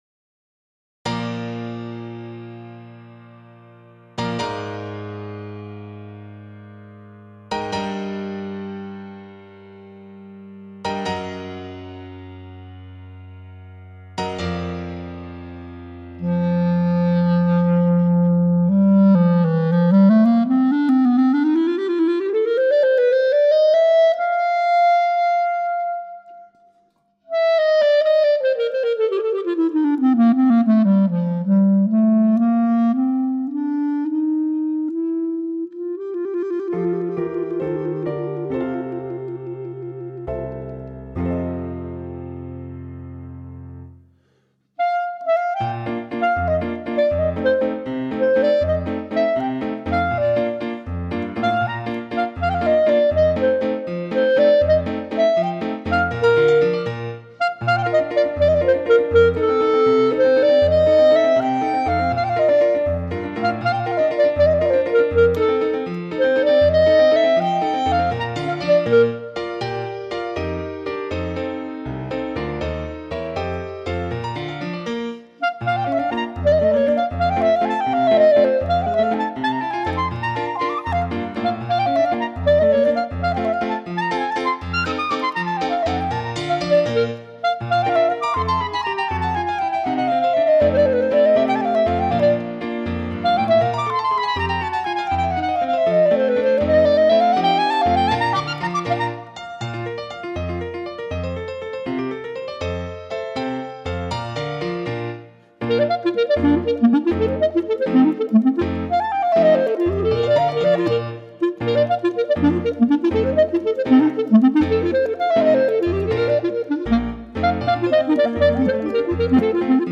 for Bb Clarinet & Piano
Clarinet Range: E1 to G4